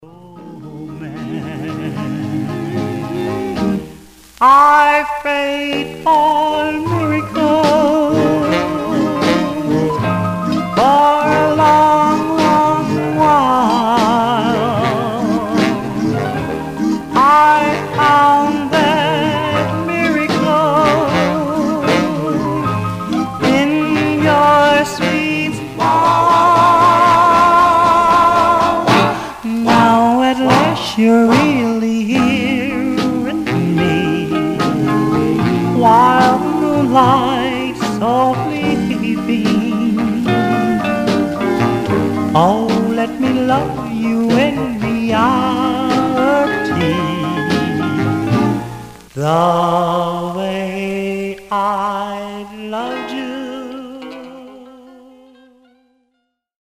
Condition Some surface noise/wear Stereo/mono Mono
Male Black Groups